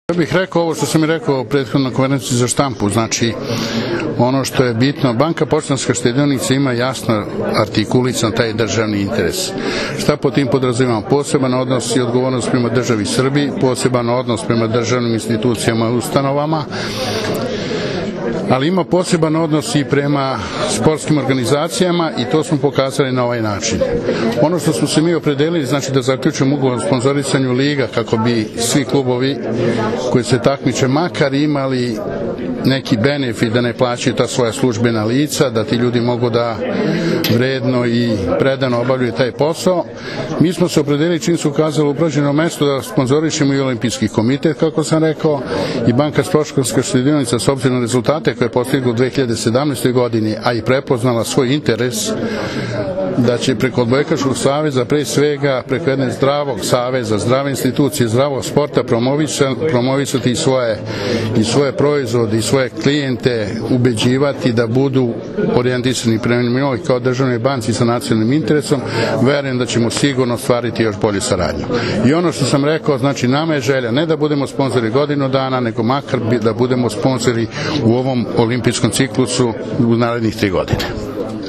Odbojkaški savez Srbije i Banka Poštanska štedionica potpisali su danas Ugovor o generalnom sponzorstvu u beogradskom hotelu „Kraun Plaza“.